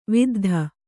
♪ viddha